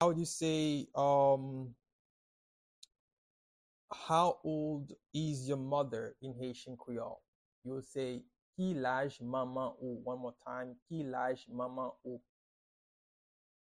Pronunciation and Transcript:
How-old-is-your-mother-in-Haitian-Creole-–-Ki-laj-manman-ou-pronunciation-by-a-Haitian-teacher.mp3